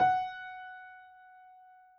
piano_066.wav